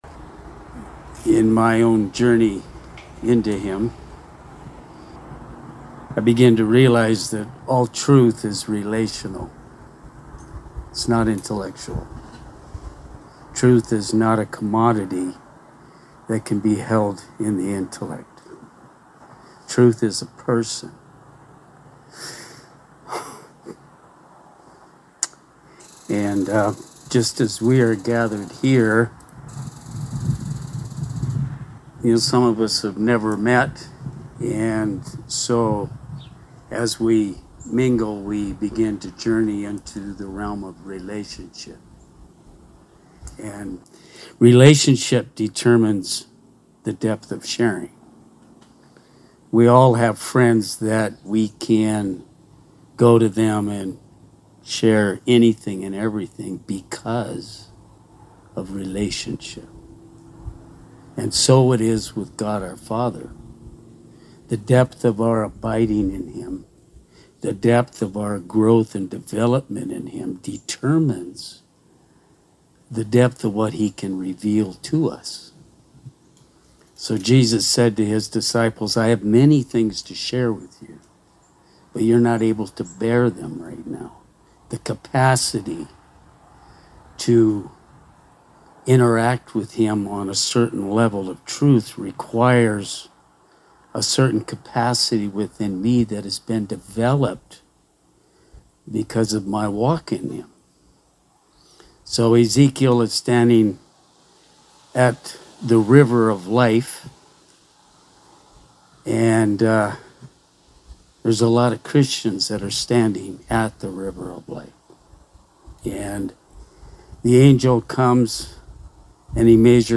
(22 minutes shared at an outdoor Conference)